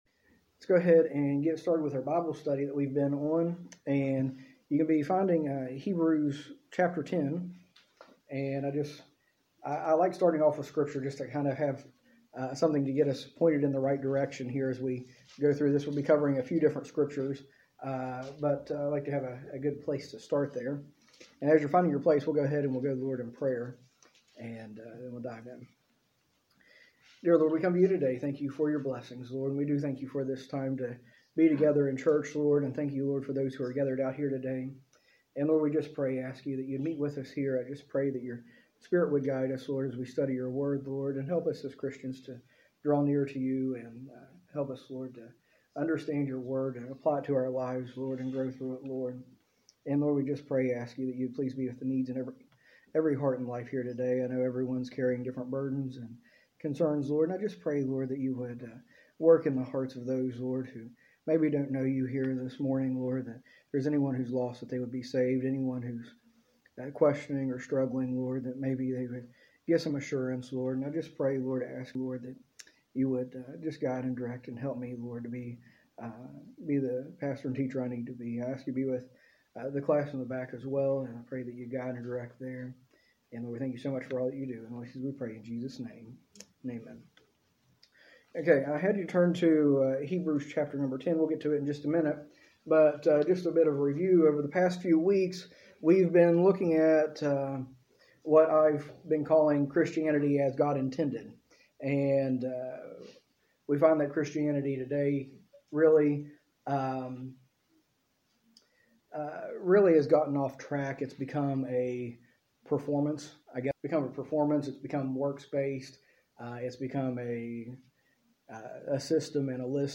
A message from the series "Practical Christianity."
In this series, taught during our adult Sunday School, we explore basic principles and teachings of Christianity that will help us live our faith in everyday life.